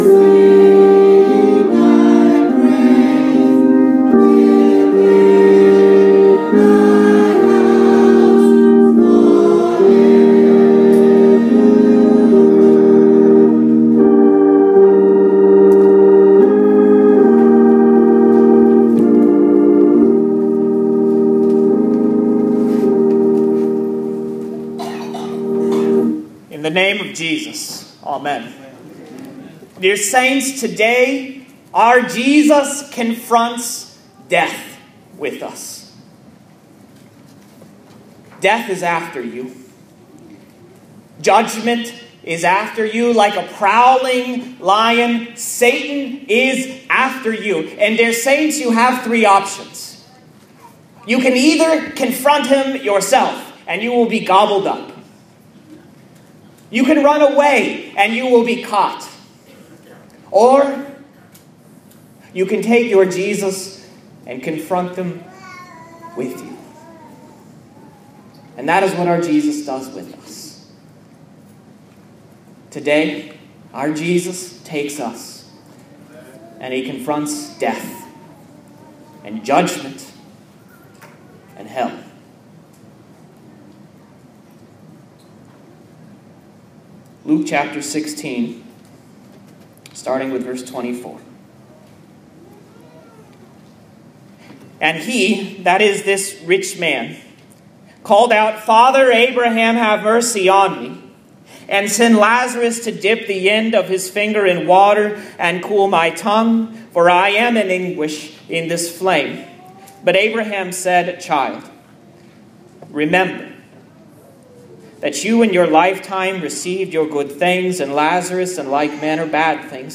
Sermon Thoughts for Luke 22:26-30, St Bartholomew, August 24, 2014